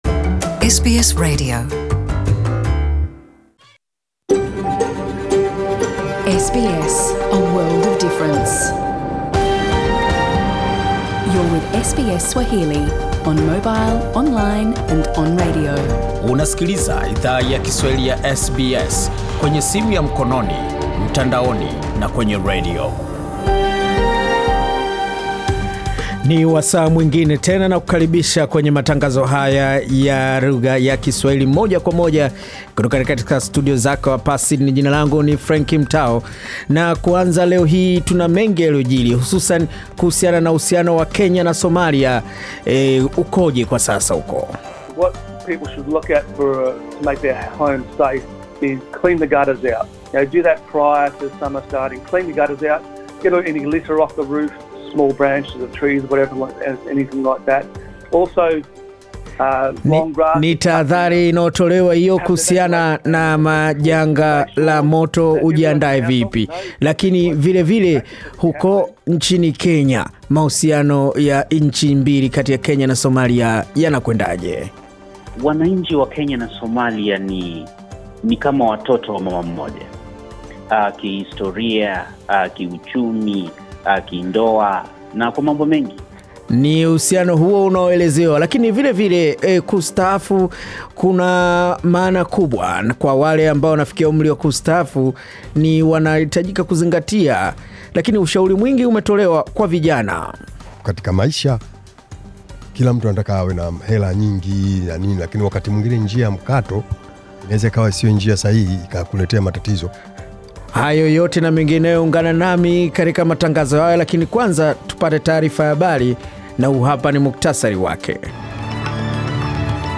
Taarifa ya habari 22 Disemba 2020